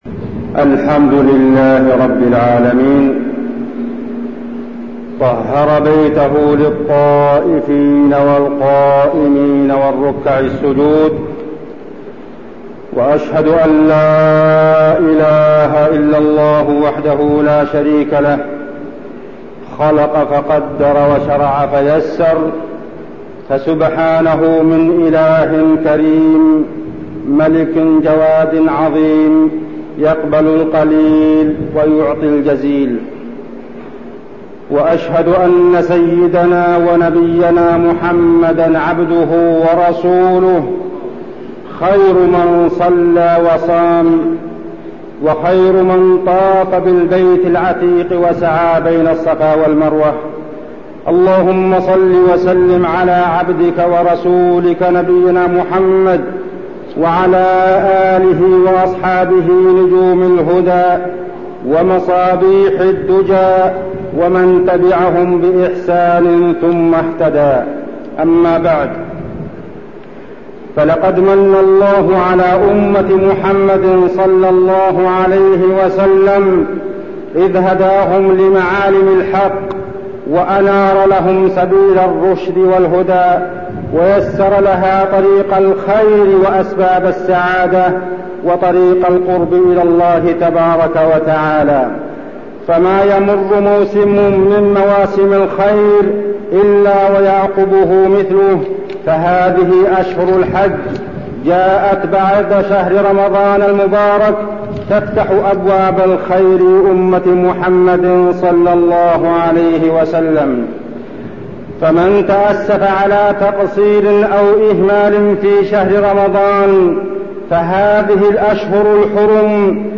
تاريخ النشر ٨ ذو القعدة ١٤٠٢ هـ المكان: المسجد النبوي الشيخ: عبدالله بن محمد الزاحم عبدالله بن محمد الزاحم شروط قبول الحج